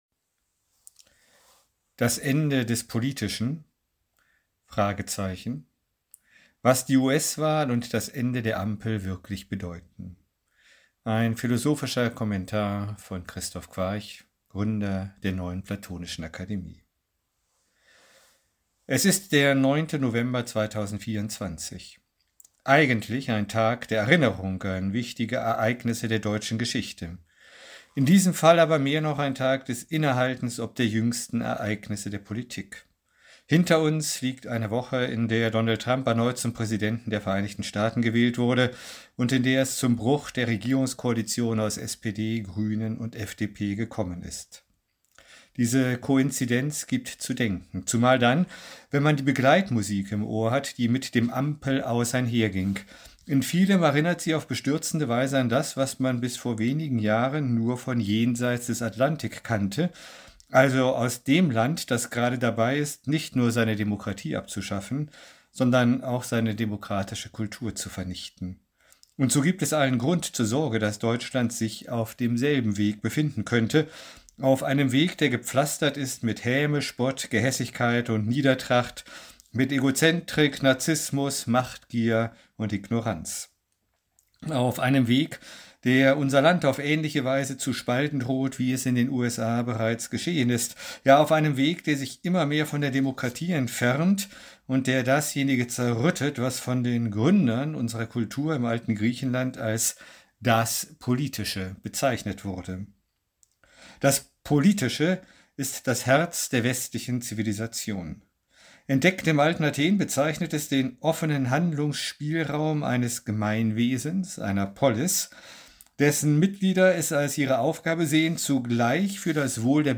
Was uns die US Wahl und das Ende der "Ampel" zu sagen haben... Ein Kommentar